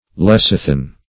Lecithin \Lec"i*thin\, n. [Gr. le`kiqos the yolk of an egg.]